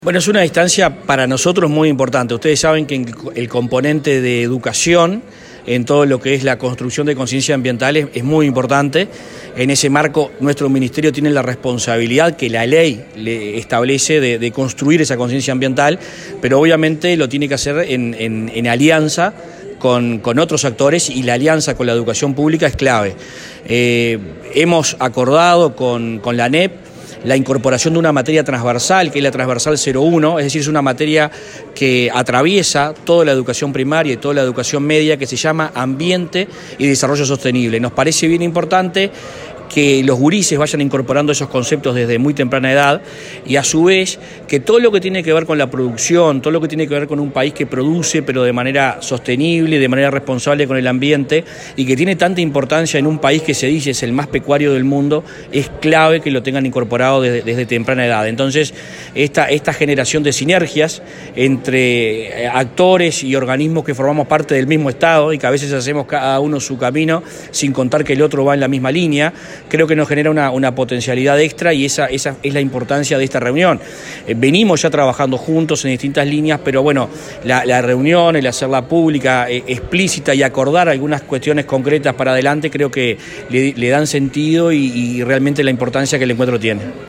Declaraciones del ministro de Ambiente, Adrián Peña